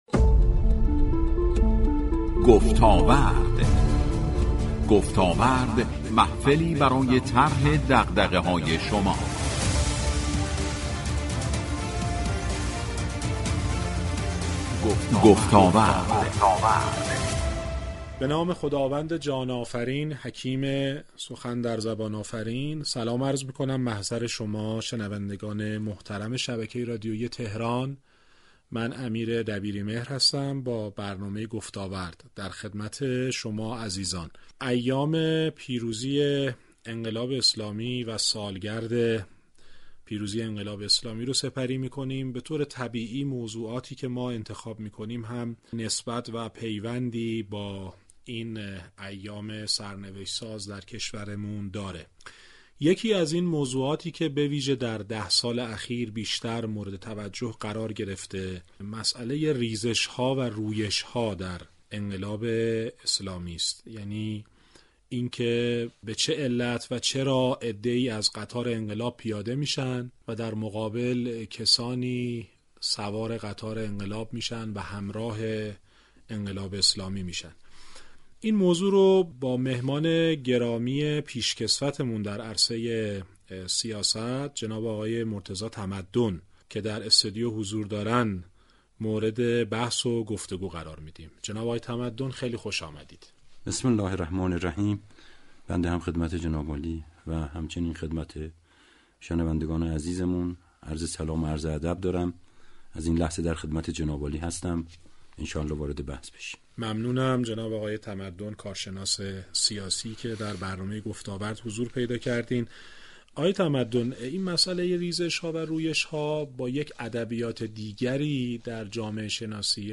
گفتاورد در این برنامه به بررسی این رویش ها و ریزش ها در گفتگو با مرتضی تمدن پرداخته است.